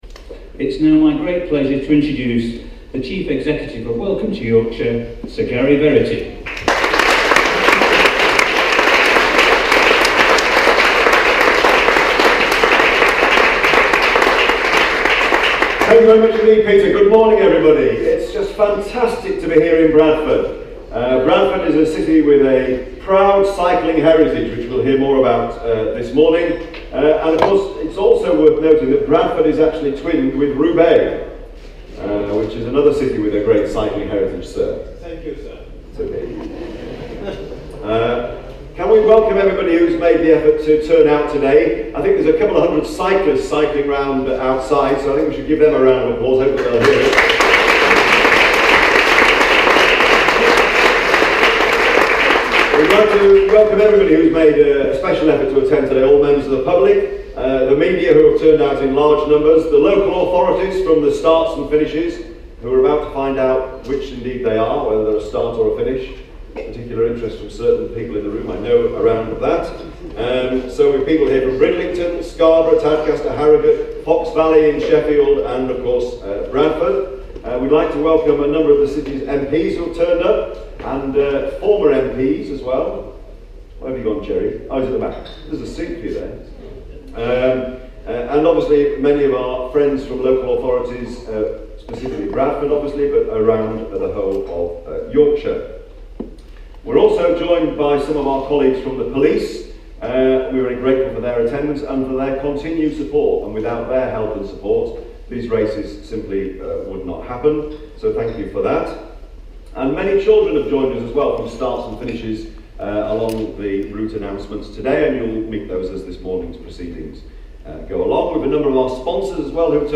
PRESS CONFERENCE: Tour de Yorkshire 2017 route details revealed by Sir Gary Verity